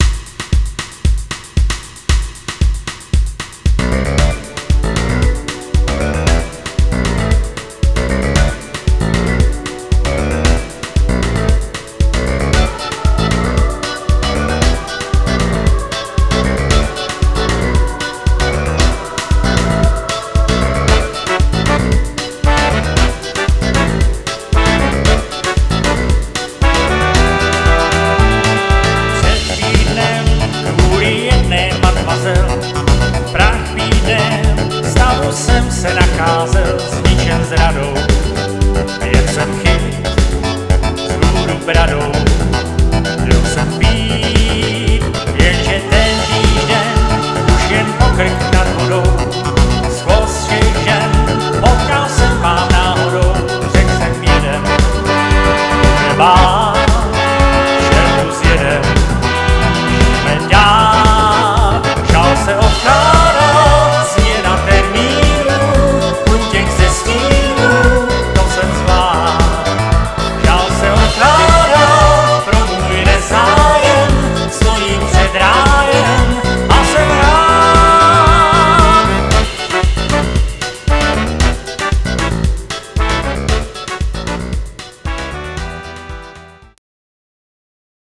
UKÁZKY SE ZPĚVÁKEM V TRIU